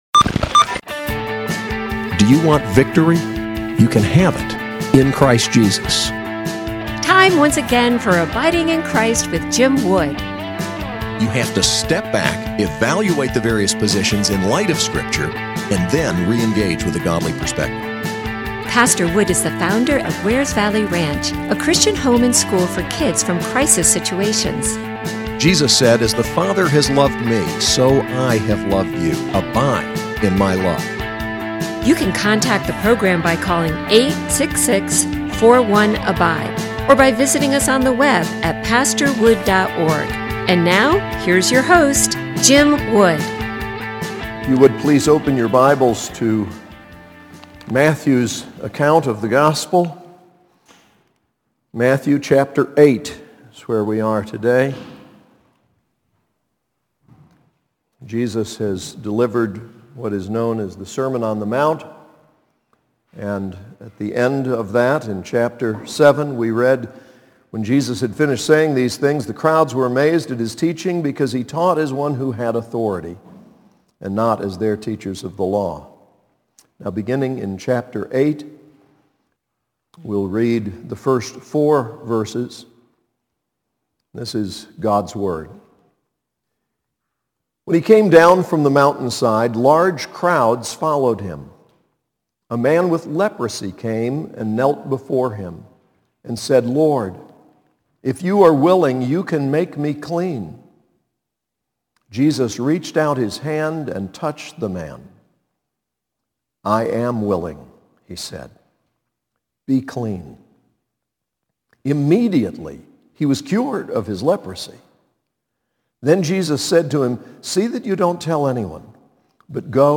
SAS Chapel: Matthew 8:1-4